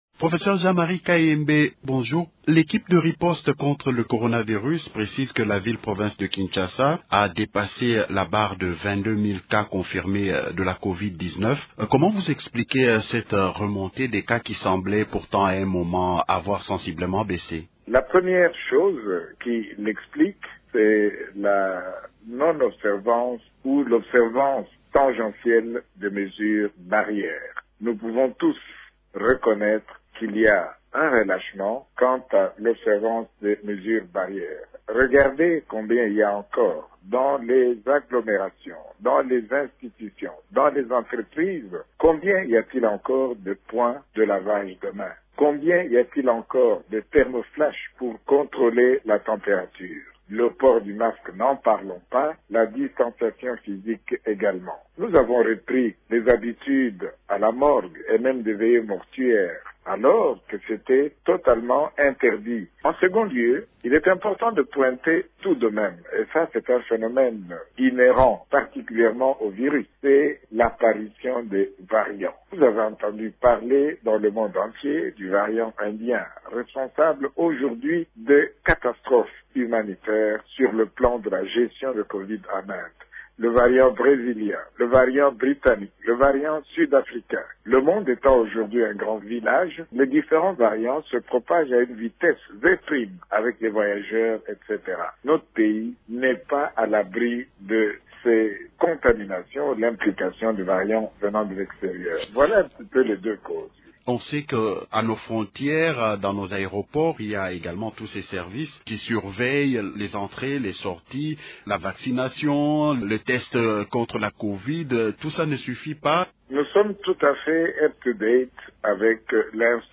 s’exprime dans une interview exclusive à Radio Okapi sur la troisième vague de cette pandémie à laquelle la RDC fait face.